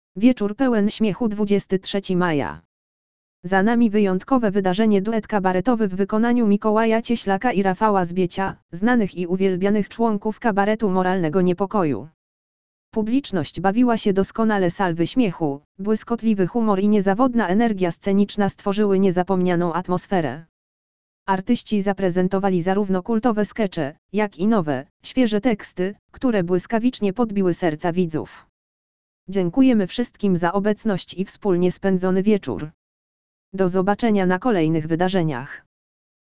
Publiczność bawiła się doskonale – salwy śmiechu, błyskotliwy humor i niezawodna energia sceniczna stworzyły niezapomnianą atmosferę. Artyści zaprezentowali zarówno kultowe skecze, jak i nowe, świeże teksty, które błyskawicznie podbiły serca widzów.